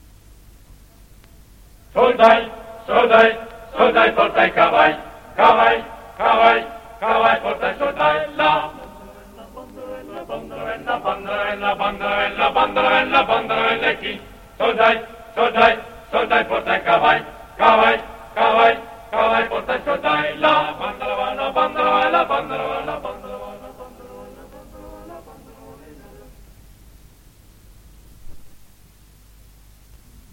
. 10 maggio 1982. 1 bobina di nastro magnetico.